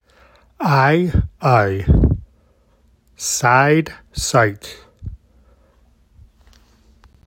Here’s my pronunciations of the two diphthongs, and side and sight. The last one has the “əɪ” diphthong.